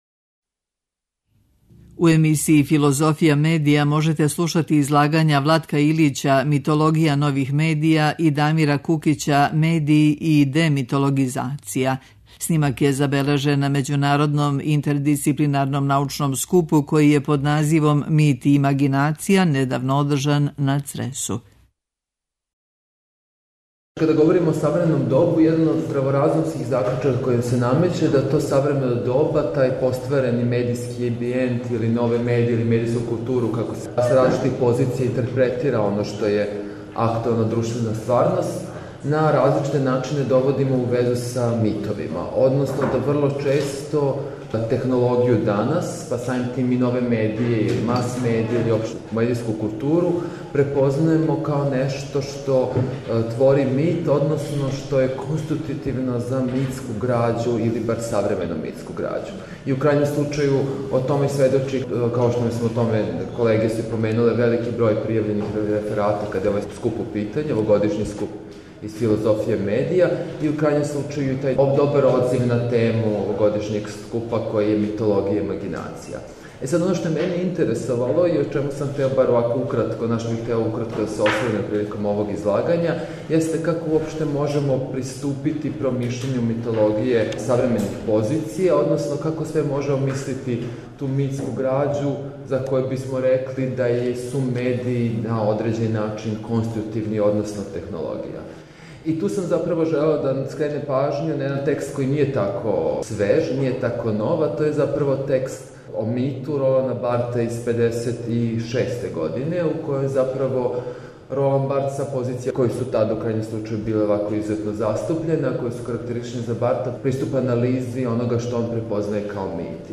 Снимак је забележен на Међународном интердисциплинарном научном скупу који је под називом „Мит и имагинација” недавно одржан на Цресу.